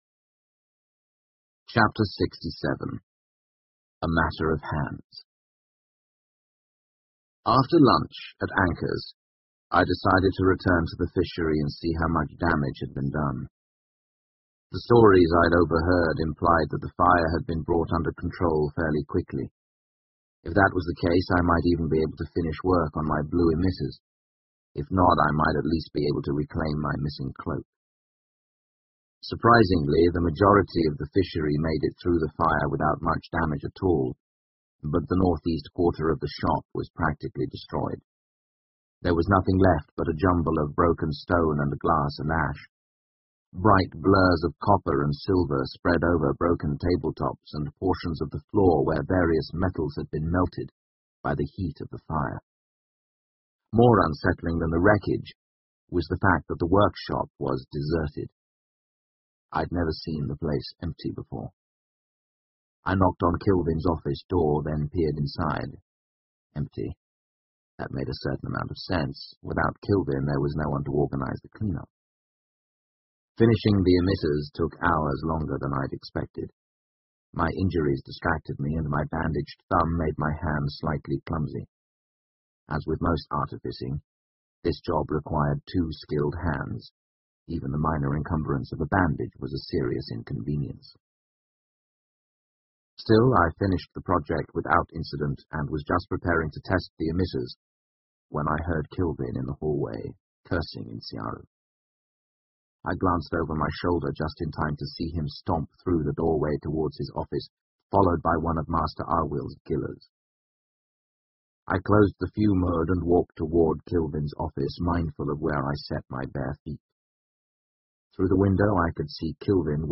英文广播剧在线听 The Name of the Wind 风之名 93 听力文件下载—在线英语听力室